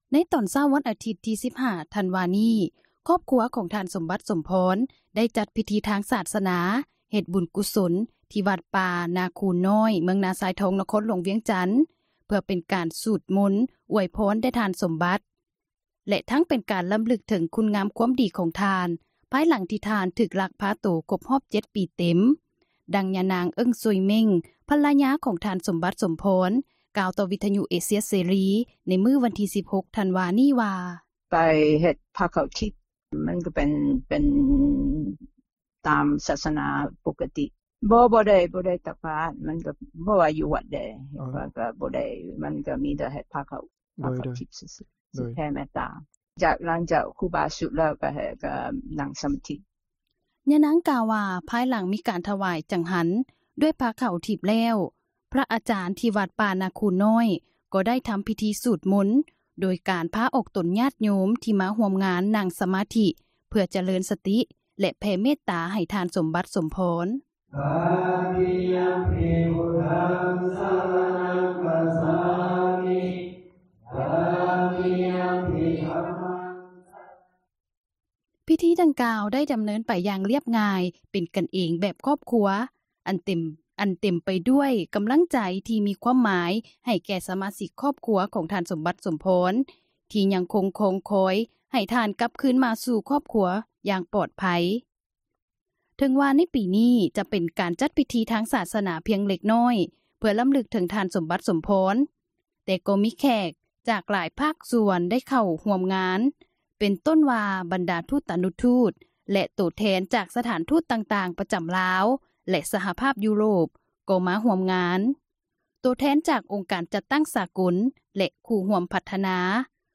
ສຽງຄຣູບາເທສນາ.